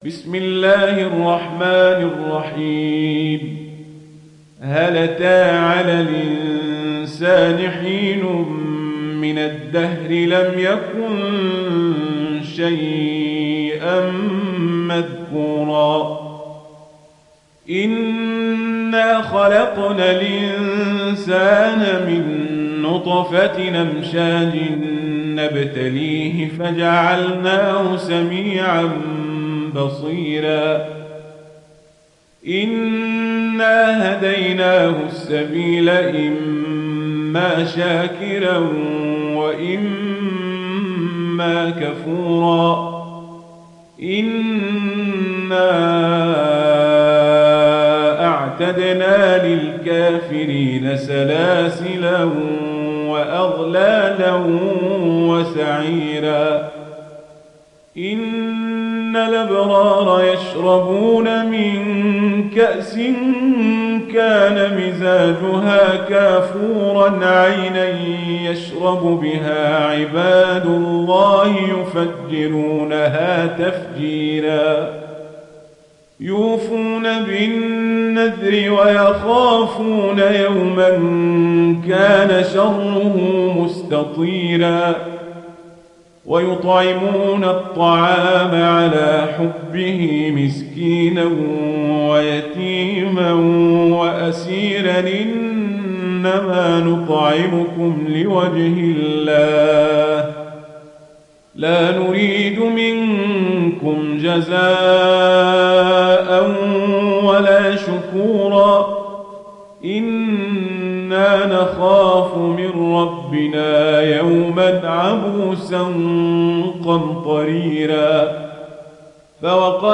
تحميل سورة الإنسان mp3 بصوت عمر القزابري برواية ورش عن نافع, تحميل استماع القرآن الكريم على الجوال mp3 كاملا بروابط مباشرة وسريعة